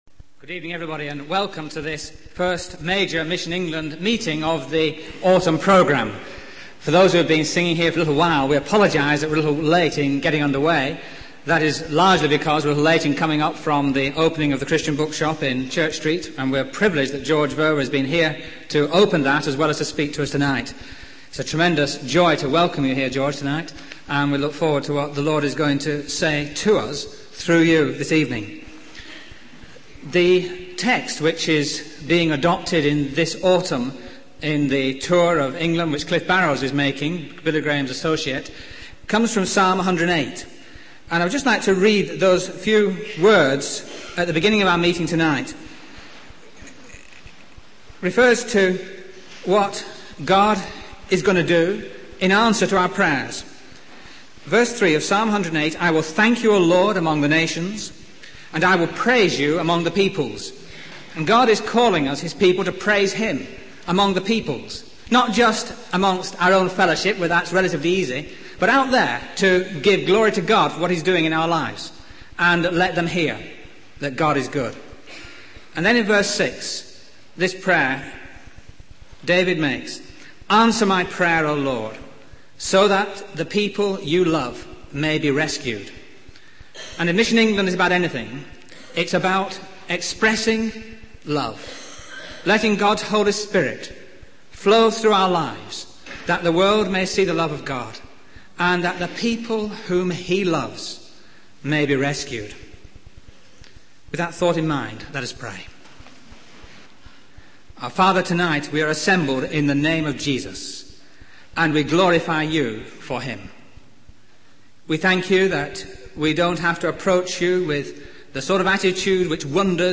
In this sermon, the preacher emphasizes the importance of hearing the voice of Jesus and responding to his call. He encourages the audience to make a personal commitment to God and receive Jesus as their savior.